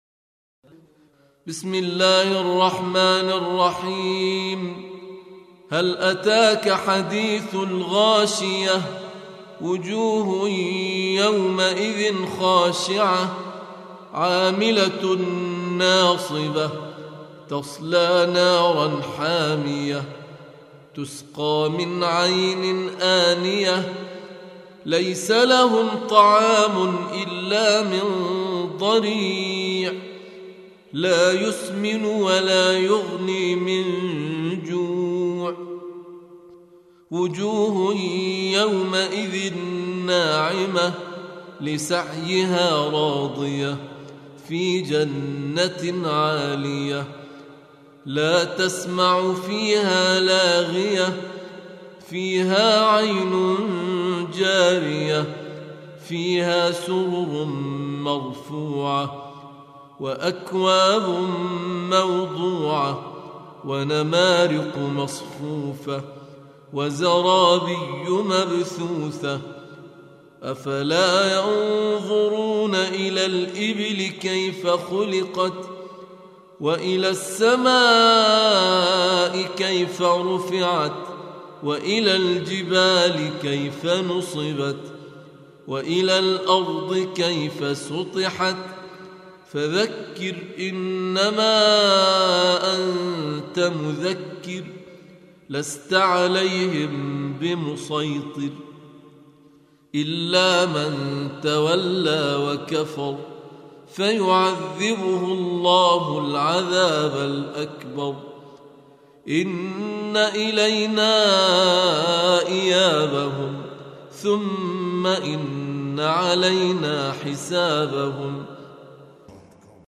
88. Surah Al-Gh�shiyah سورة الغاشية Audio Quran Tarteel Recitation
Surah Sequence تتابع السورة Download Surah حمّل السورة Reciting Murattalah Audio for 88.